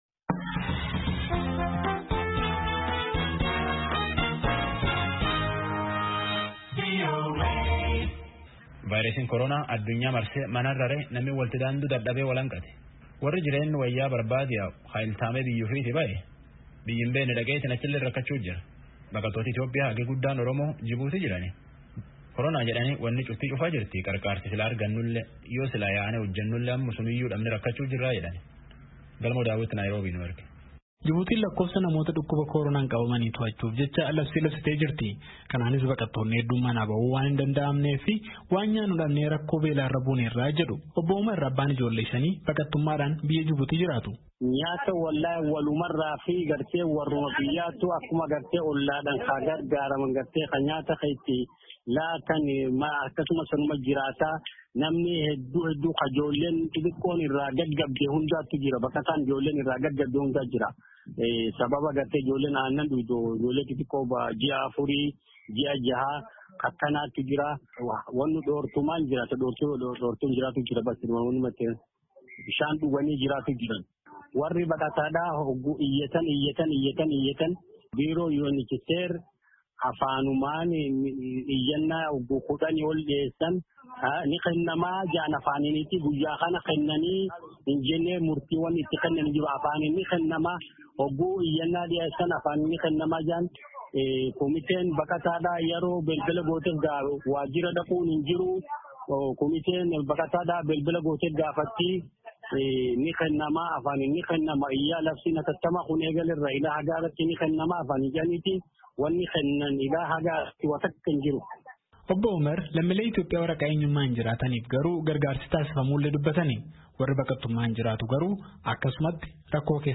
Dhalattoonni Oromoo hedduun yoo VOA’f dubbatan, yoo biyyas jiraatan ijoollee isaaniis carraa barnoota sadarkaa lammaffaa argachuu akka hin dandeenye dubbatan.